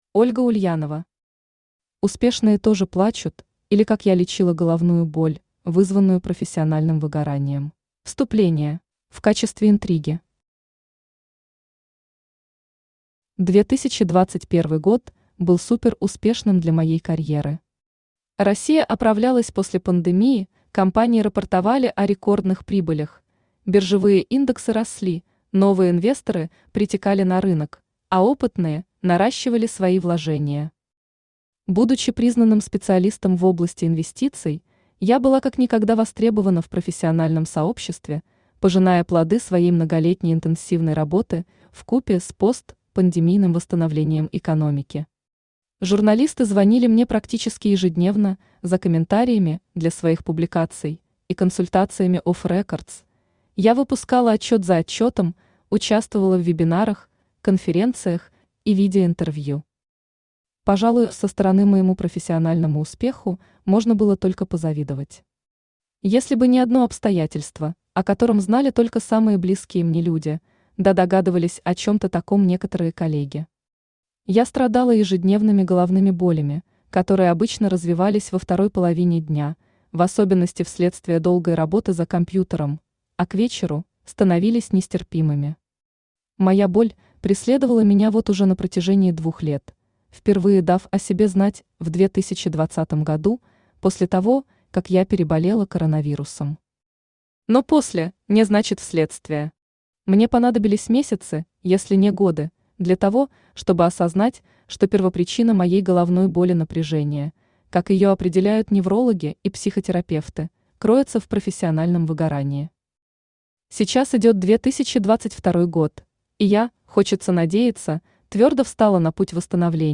Аудиокнига Успешные тоже плачут, или Как я лечила головную боль, вызванную профессиональным выгоранием | Библиотека аудиокниг
Читает аудиокнигу Авточтец ЛитРес.